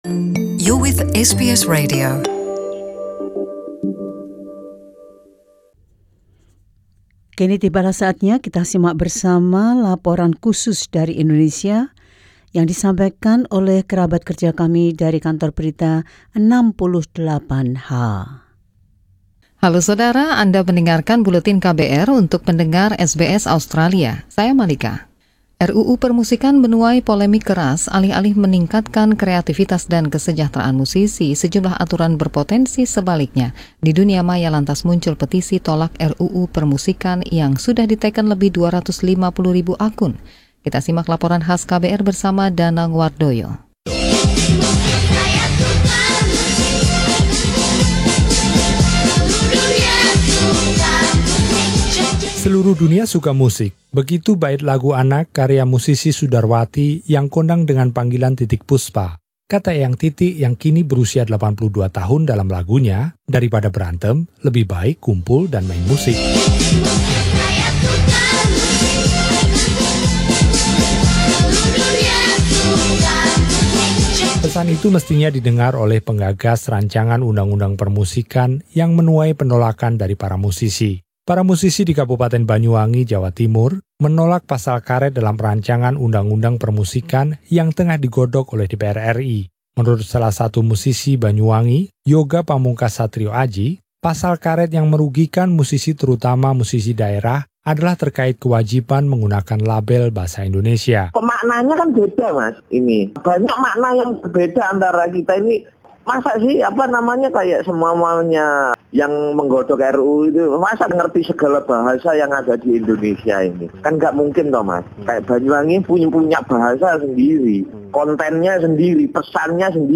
Laporan KBR 68H: Kor tanpa harmoni.